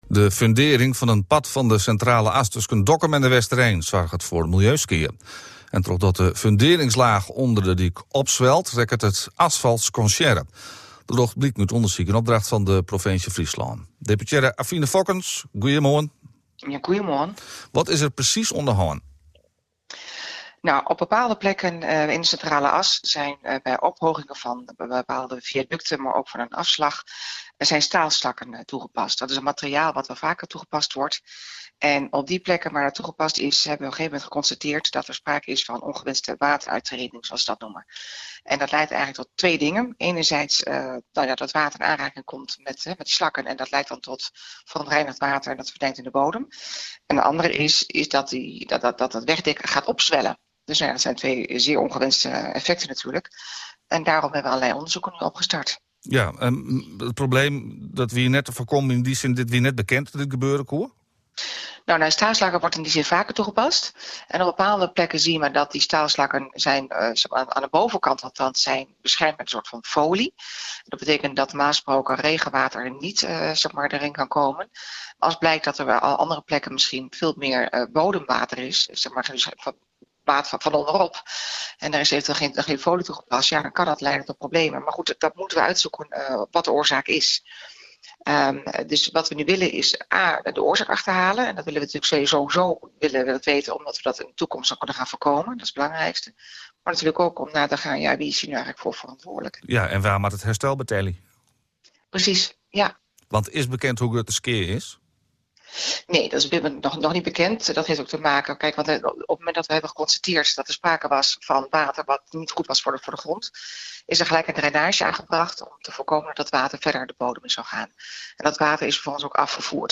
Luister naar het gesprek met gedeputeerde Avine Fokkens